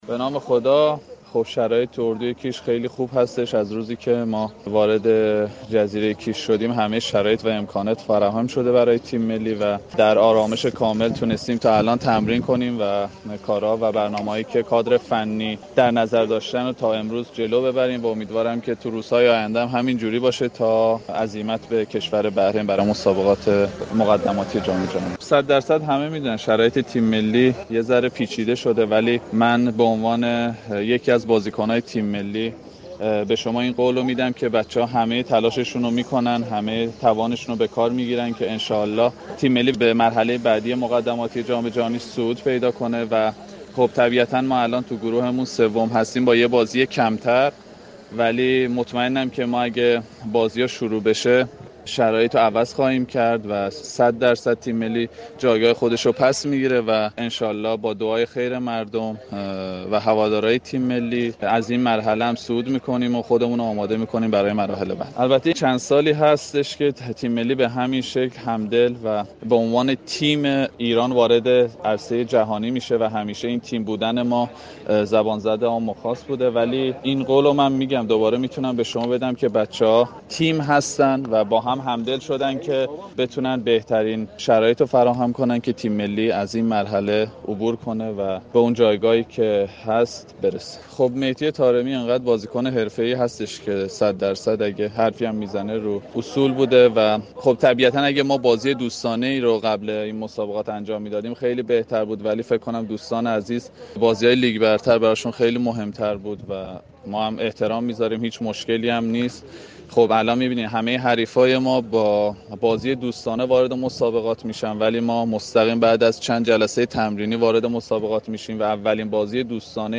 كریم انصاری فرد مهاجم تیم ملی فوتبال كشور در مصاحبه با رادیو ورزش گفت: شرایط اردوی كیش بسیار خوب است و بازیكنان با همدلی بیشتر از این مرحله قطعا عبور خواهند كرد.